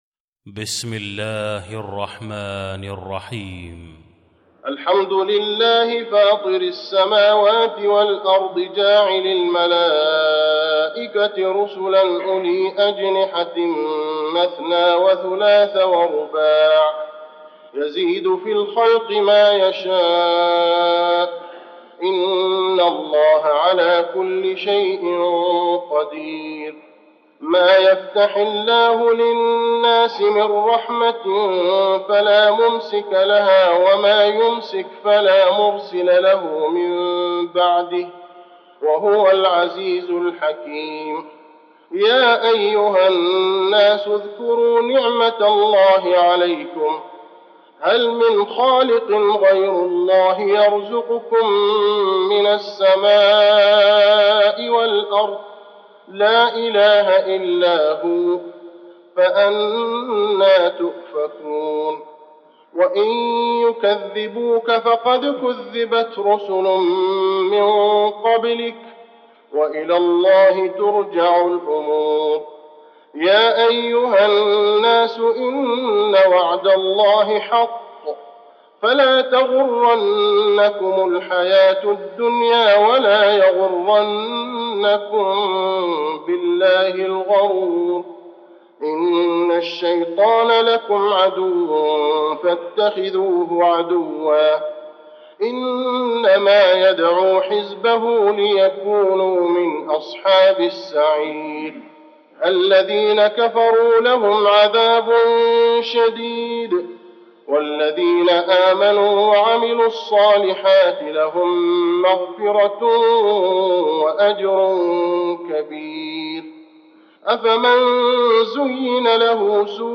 المكان: المسجد النبوي فاطر